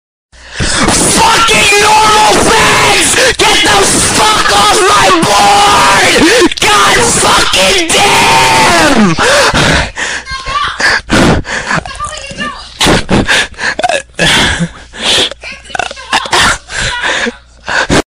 cobfederate battle cry.mp3 📥 (283.6 KB)
After a couple minutes of fighting, the cobfederates charge the Soyunionist position with xheir bayonets as they yell out their fierce battle cry.